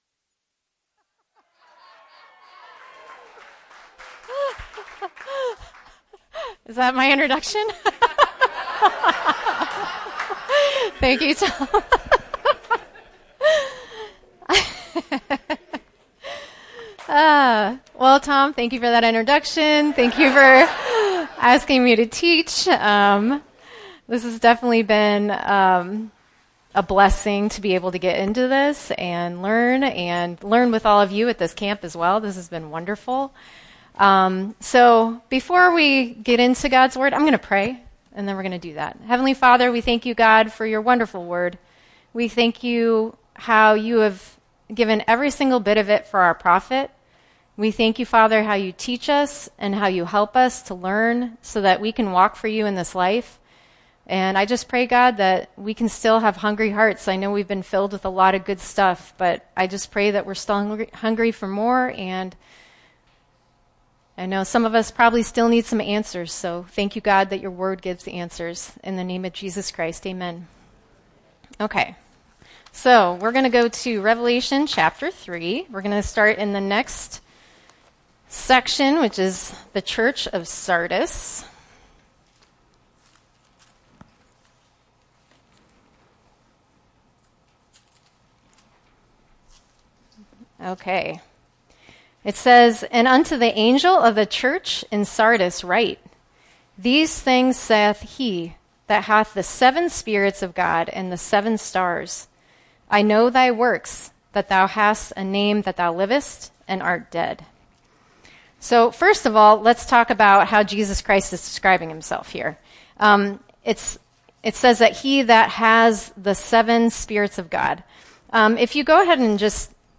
Part 5 of a series of verse-by-verse teachings on the opening chapters of the book of Revelation. There is great practical learning for us in the messages from our Lord to the churches.